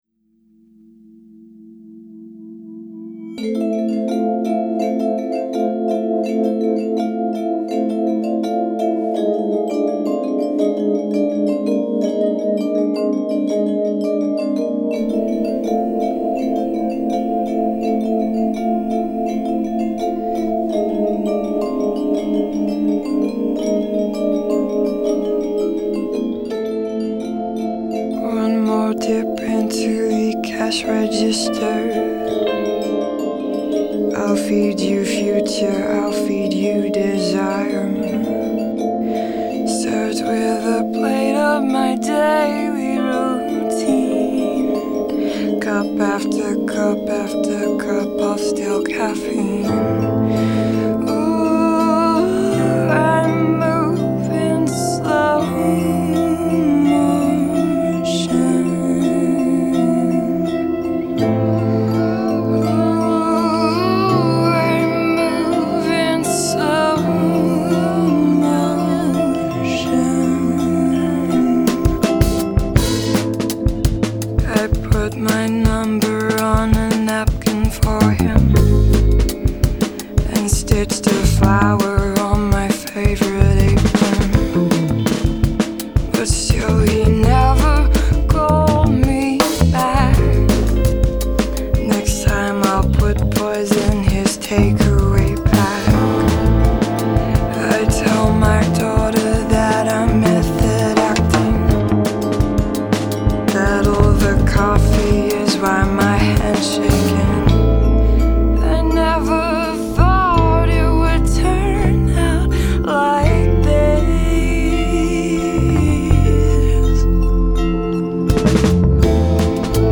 haunting vocals
darker side of folk blues
bewitching and ominous